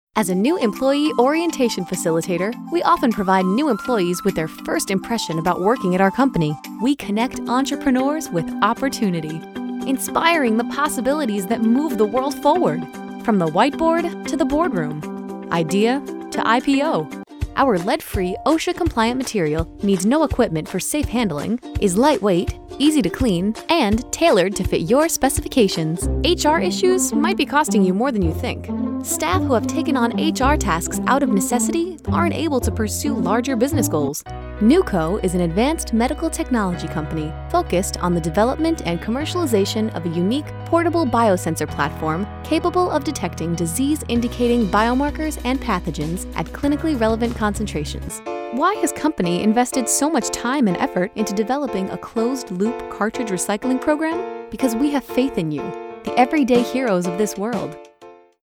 Female Voice Over, Dan Wachs Talent Agency.
Bright, Friendly, Warm, Credible.
Corporate